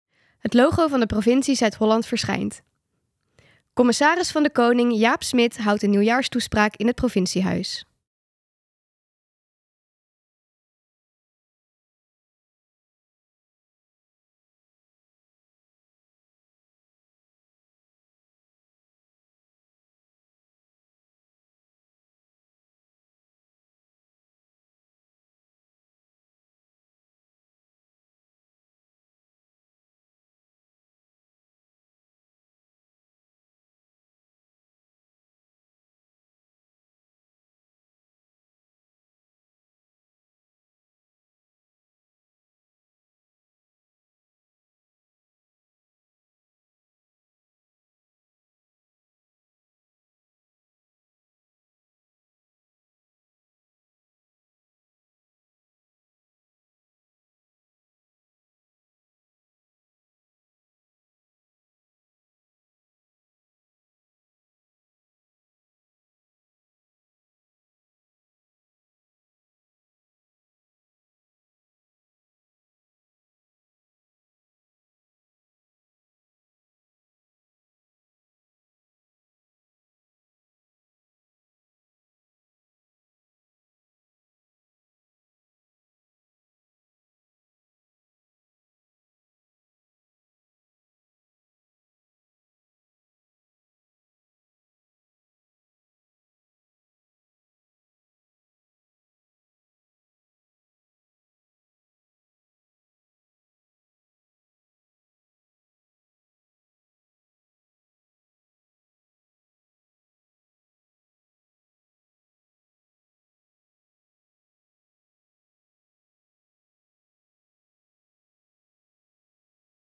Toespraak Nieuwjaarsreceptie
Op woensdag 10 januari vond de Nieuwjaarsreceptie van de Provincie Zuid-Holland plaats waar de commissaris van de Koning, Jaap Smit, zijn jaarlijkse toespraak hield.